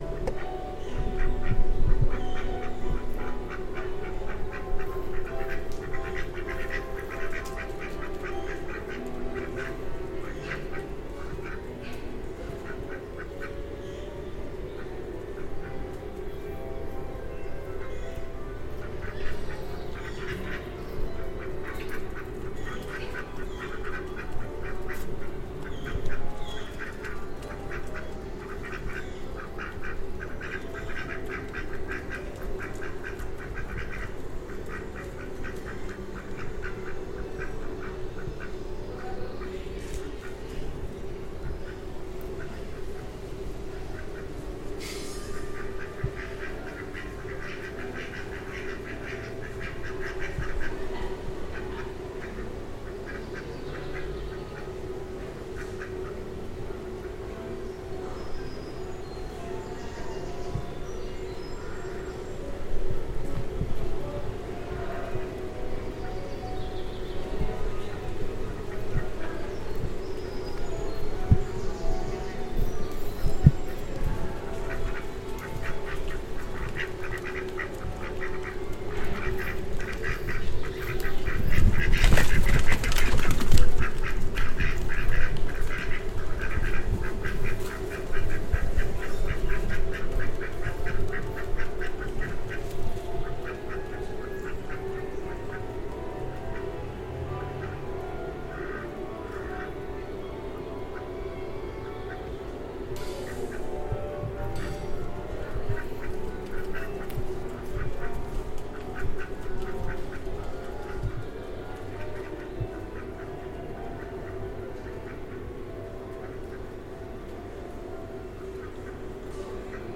two-ducks.mp3